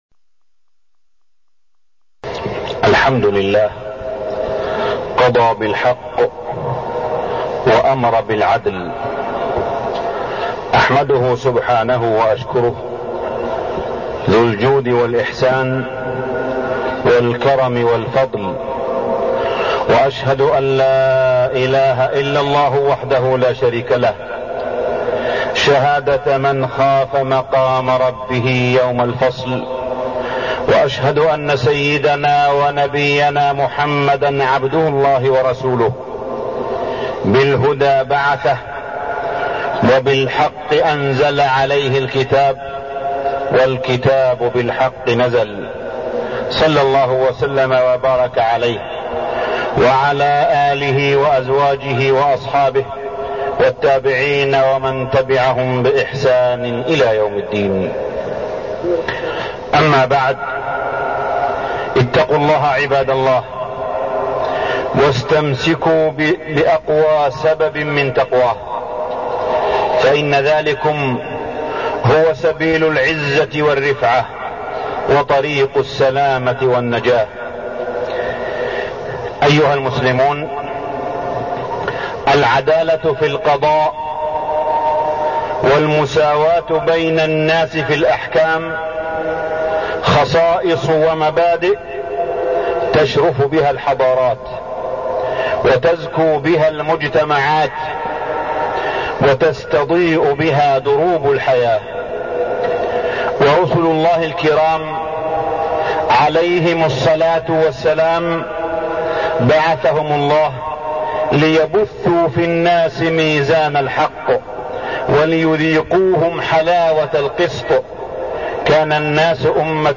تاريخ النشر ١٢ جمادى الأولى ١٤١٣ هـ المكان: المسجد الحرام الشيخ: معالي الشيخ أ.د. صالح بن عبدالله بن حميد معالي الشيخ أ.د. صالح بن عبدالله بن حميد محبة الله